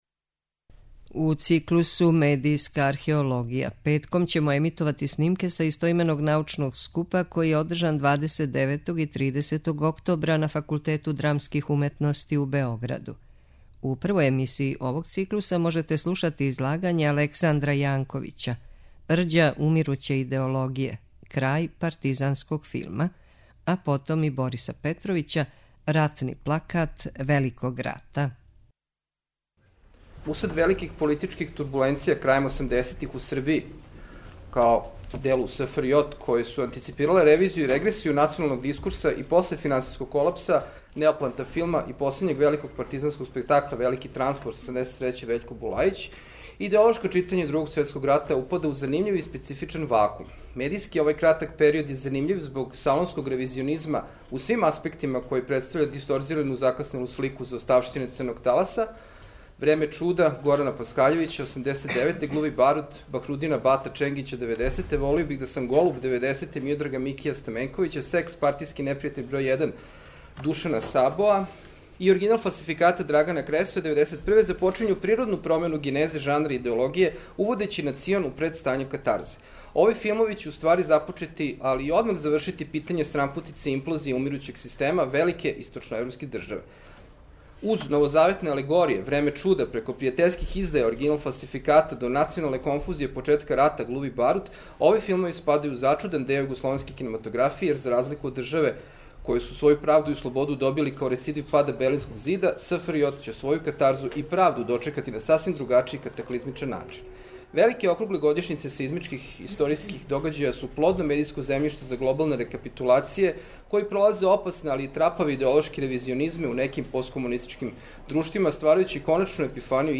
У циклусу МЕДИЈСКА АРХЕОЛОГИЈА петком ћемо емитовати снимке са истоименог научног скупа који је одржан 29. и 30. октобра на Факултету драмских уметности у Београду.